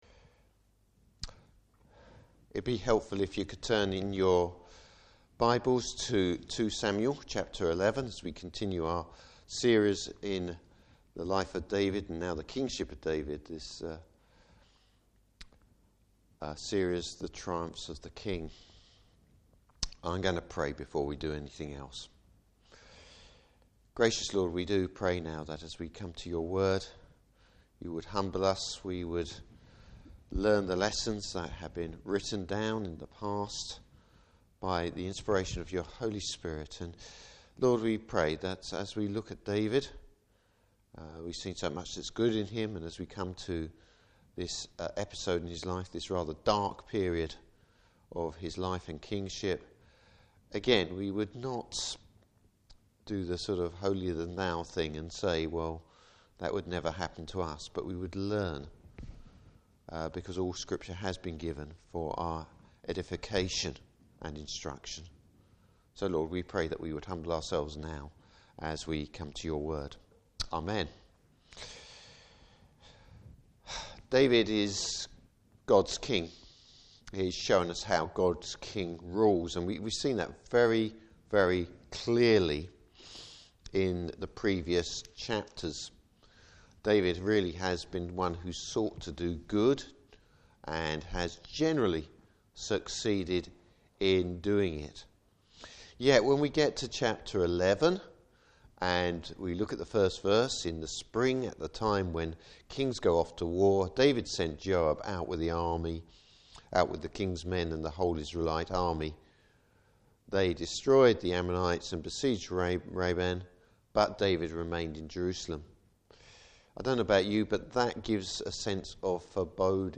Service Type: Evening Service The slippery path temptation can put us on!